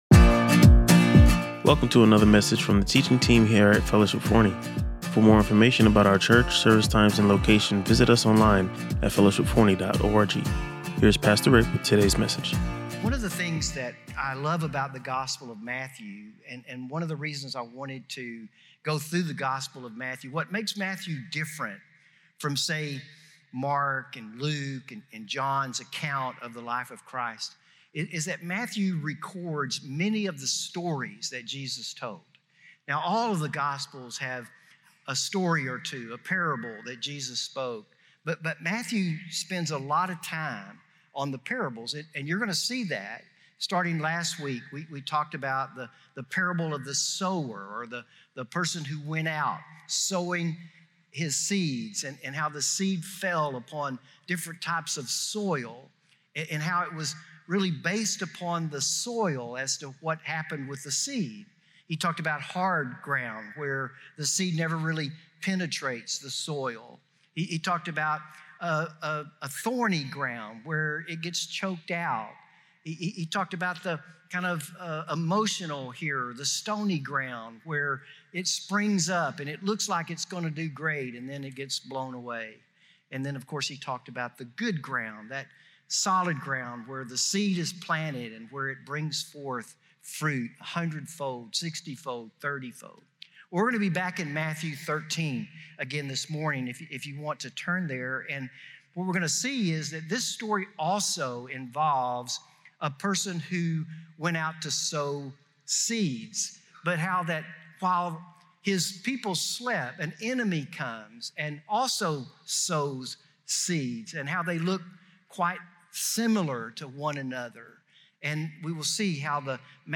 He emphasized that while it can be difficult to distinguish between them, God allows this mixture for His divine purpose. The sermon focused on our responsibility to plant seeds of faith rather than attempt to separate people, as God has fixed a specific time when He will return to make the final separation.